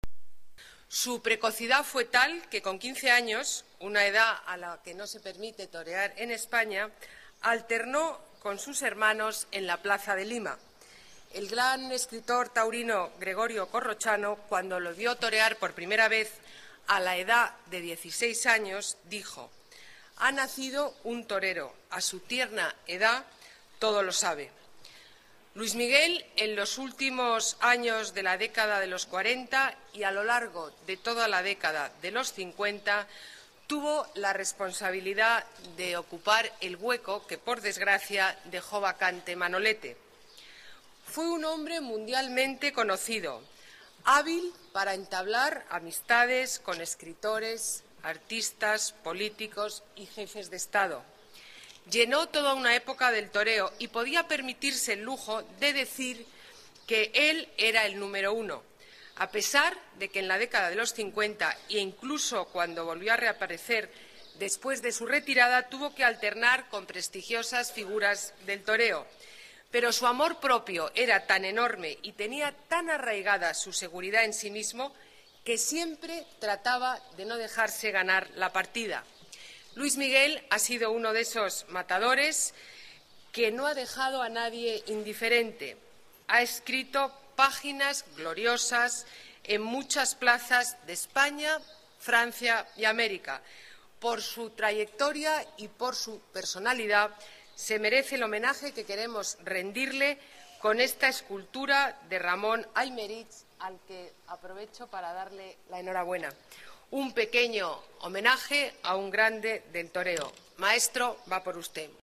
La alcaldesa, Ana Botella, asiste a la colocación de la estatua del torero en la explanada de la plaza de toros
Nueva ventana:Declaraciones de la alcaldesa, Ana Botella